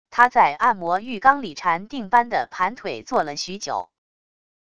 他在按摩浴缸里禅定般地盘腿坐了许久wav音频生成系统WAV Audio Player